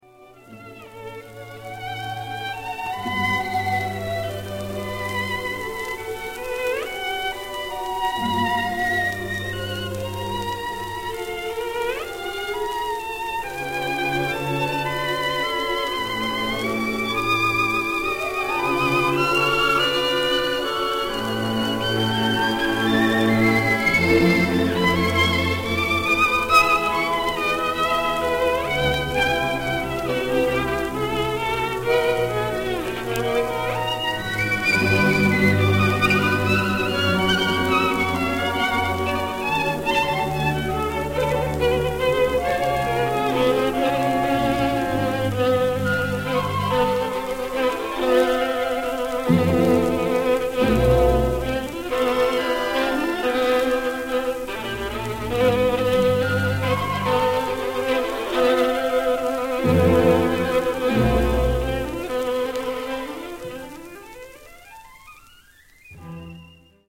(1935年8月23日ロンドン、アビー・ロードEMI 第1スタジオ録音)